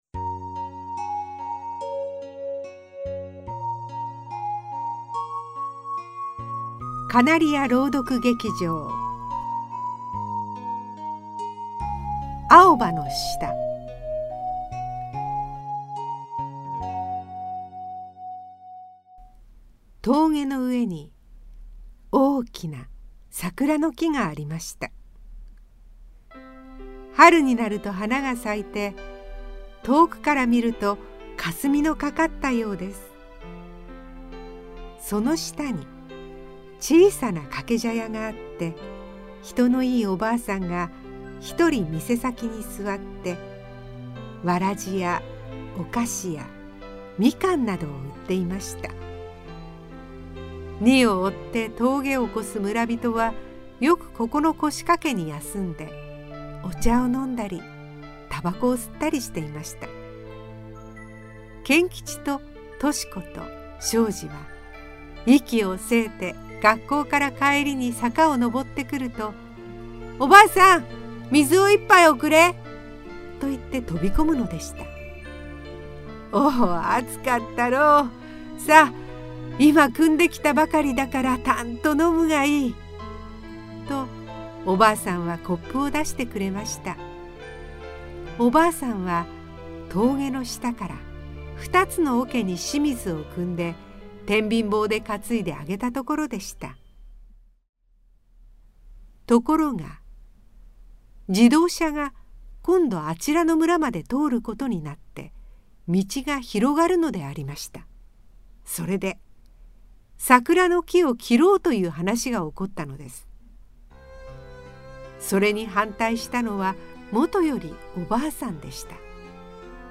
地域の発展と自然環境の保護、みんなの思いが大きな力になることなど、今の時代に改めて考えたいテーマを含んでいます。あたたかな朗読と可愛いイラストで、お楽しみください。